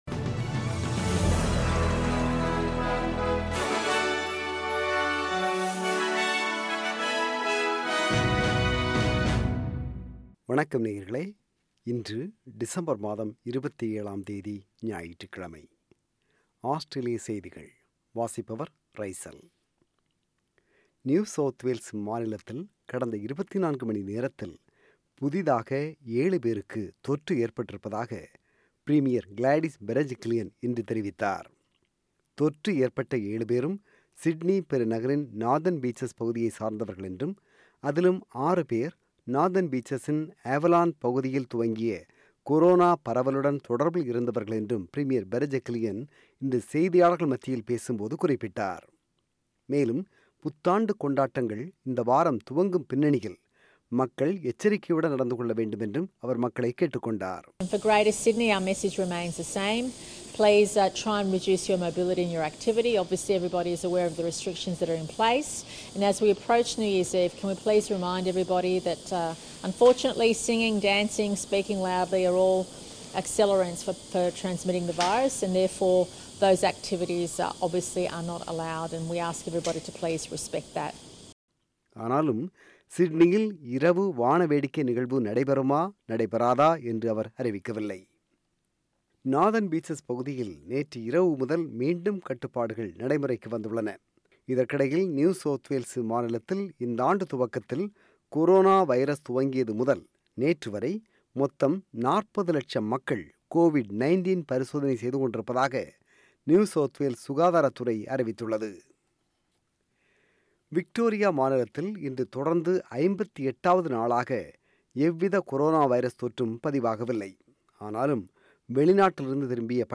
Australian News: 27 December 2020 – Sunday